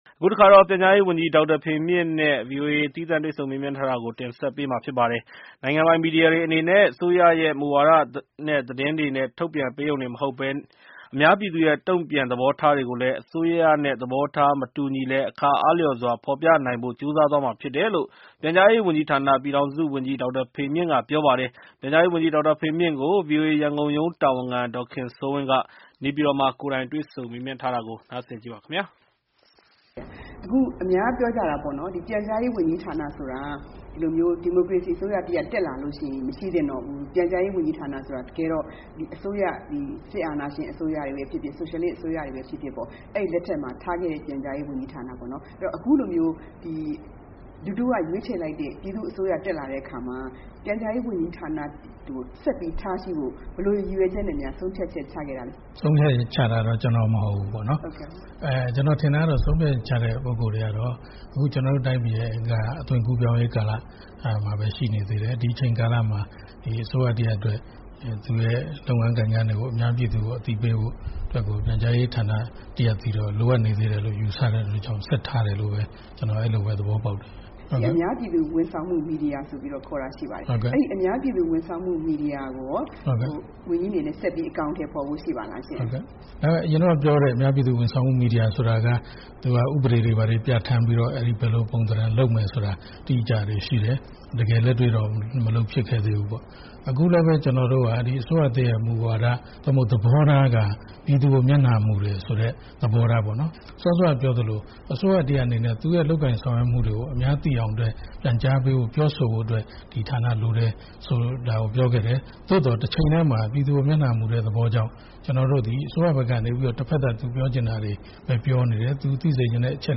ပြည်ထောင်စုဝန်ကြီး ဒေါက်တာဖေမြင့်နဲ့ VOA တွေ့ဆုံမေးမြန်း